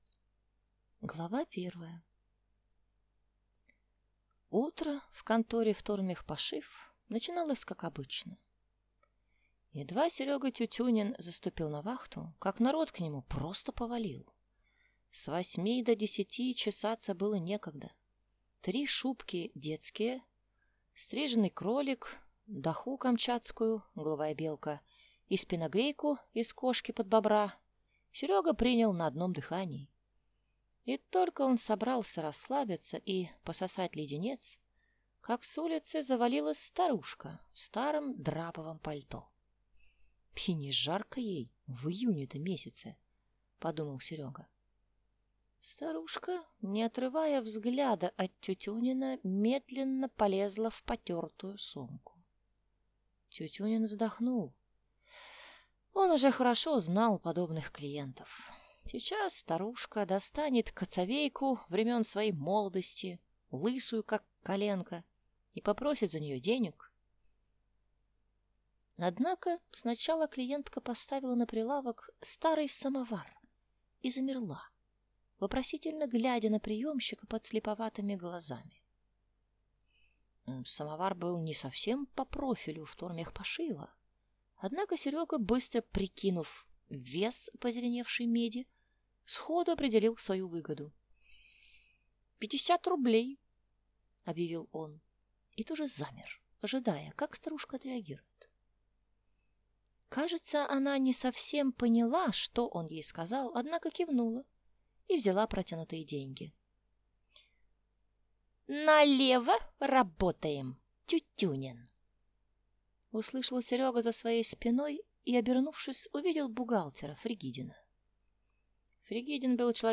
Аудиокнига Тютюнин против ЦРУ | Библиотека аудиокниг